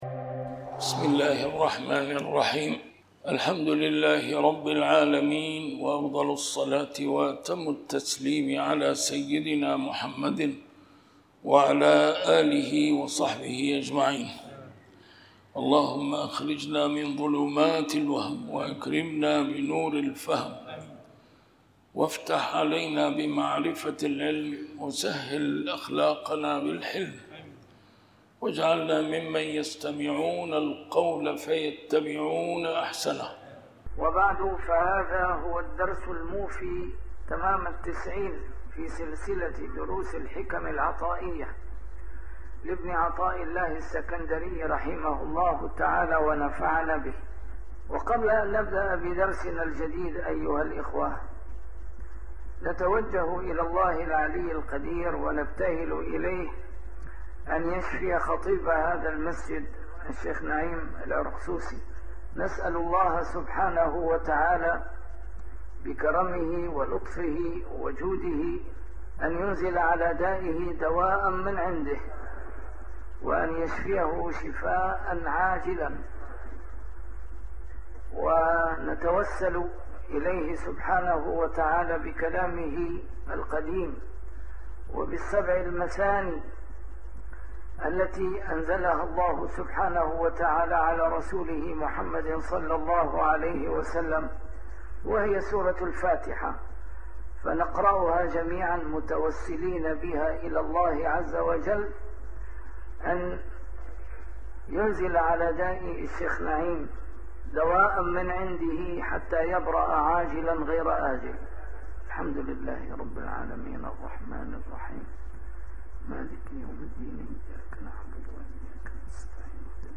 A MARTYR SCHOLAR: IMAM MUHAMMAD SAEED RAMADAN AL-BOUTI - الدروس العلمية - شرح الحكم العطائية - الدرس رقم 90 شرح الحكمة 68